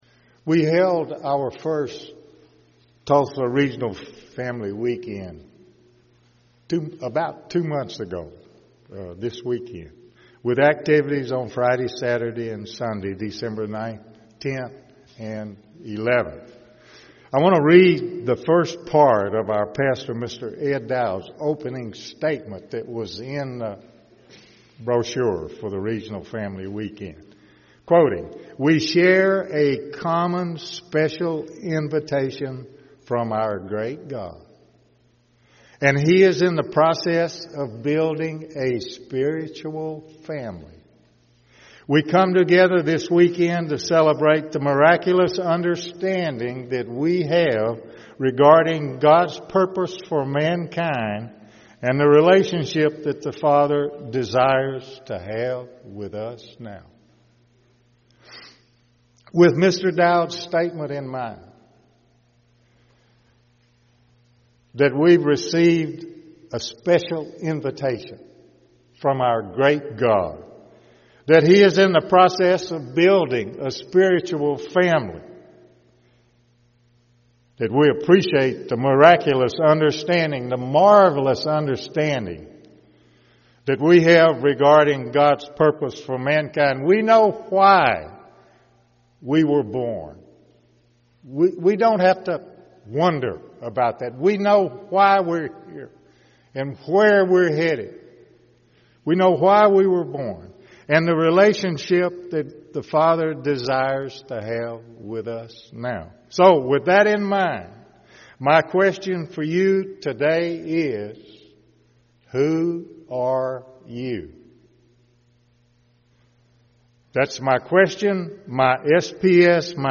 Sermons
Given in Tulsa, OK